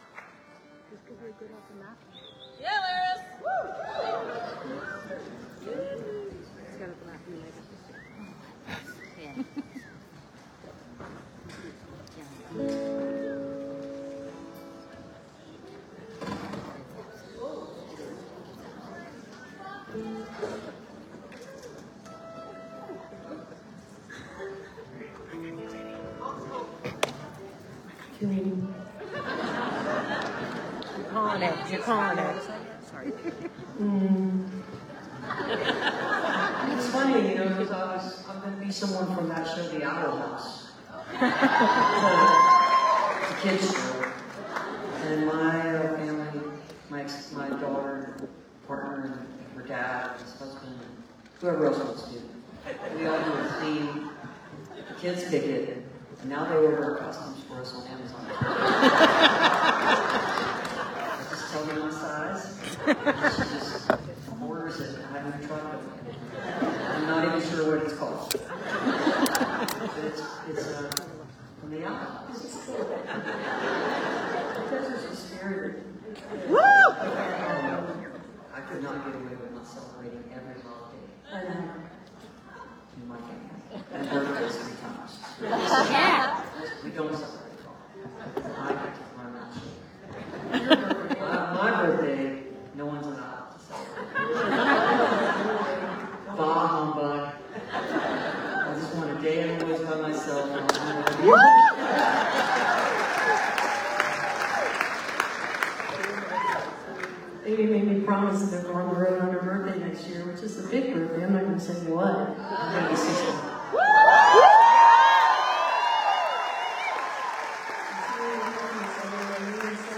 12. talking with the crowd (3:24)